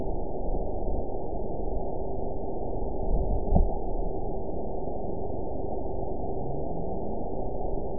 event 912024 date 03/15/22 time 17:19:15 GMT (3 years, 2 months ago) score 8.94 location TSS-AB05 detected by nrw target species NRW annotations +NRW Spectrogram: Frequency (kHz) vs. Time (s) audio not available .wav